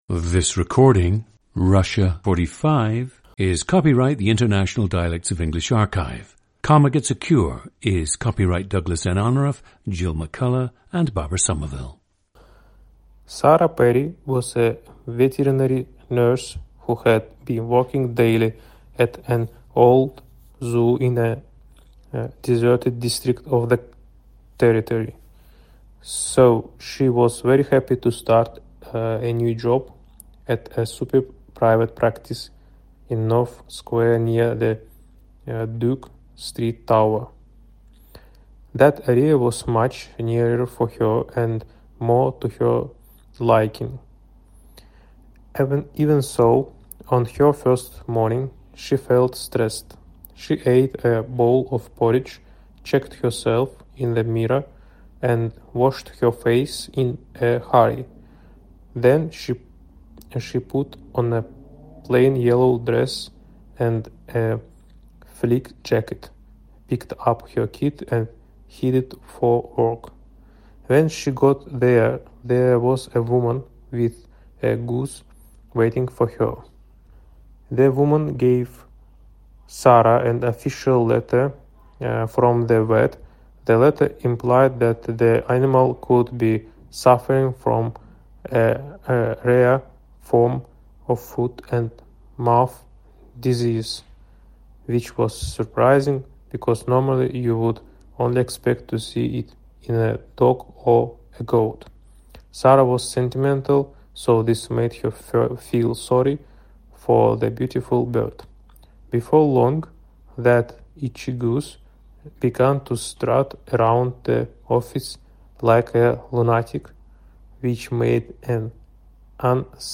GENDER: male
OTHER INFLUENCES ON SPEECH: none
• Recordings of accent/dialect speakers from the region you select.